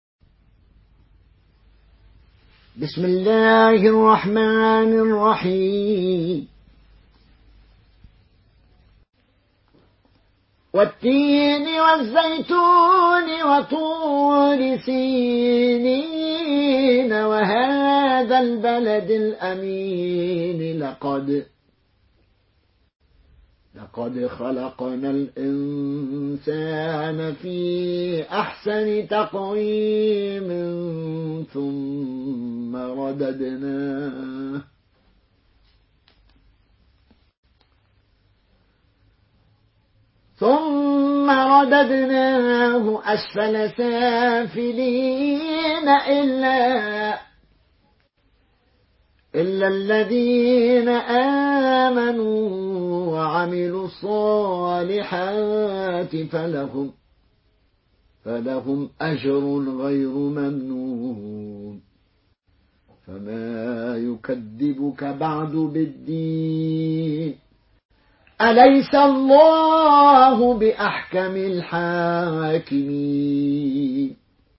مرتل قالون عن نافع